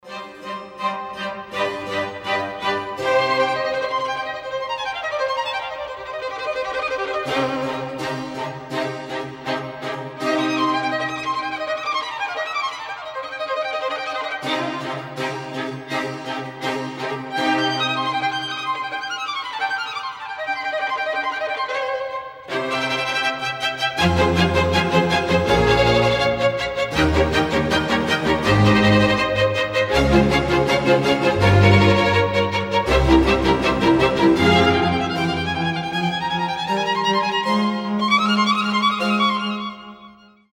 инструментальные , классические
виолончель , оркестр
без слов